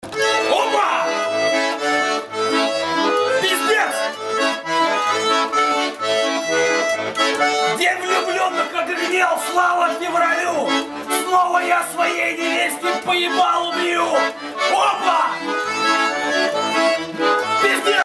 • Качество: 256, Stereo
веселые
аккордеон
Баян
Угарная матерная песня)